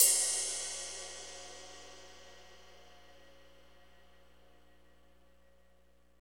Index of /90_sSampleCDs/Northstar - Drumscapes Roland/DRM_Slow Shuffle/CYM_S_S Cymbalsx